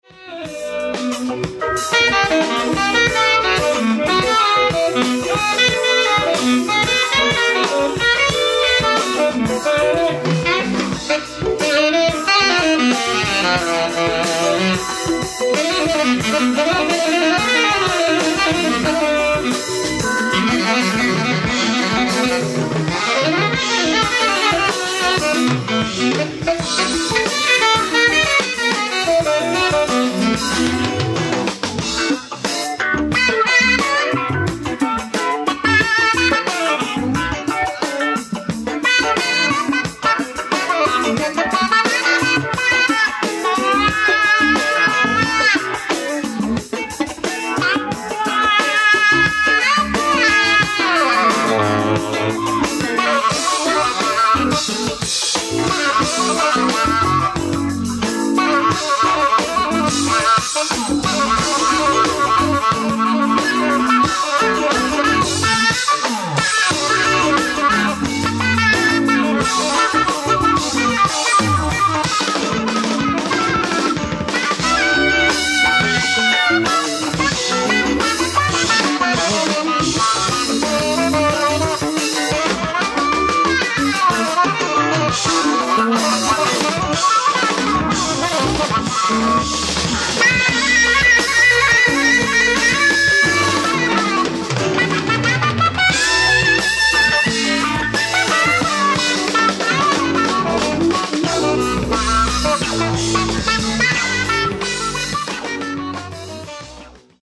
ライブ・アット・マイ・ファザーズ・パレス、ロスリン、ニューヨーク 10/06/1978
※試聴用に実際より音質を落としています。